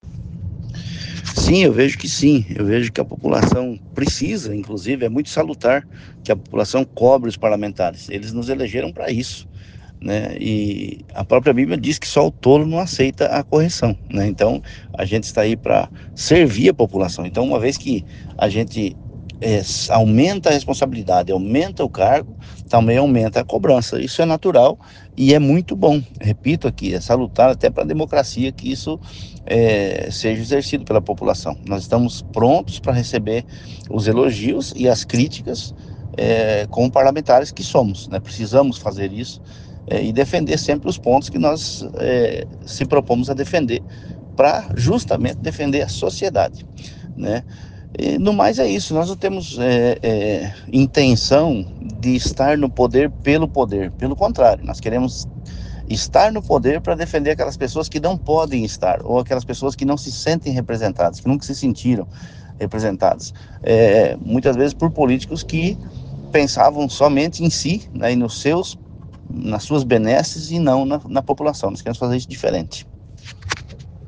OUÇA NA ÍNTEGRA A ENTREVISTA QUE O SITE OPINIÃO FEZ COM O DEPUTADO GILBERTO CATTANI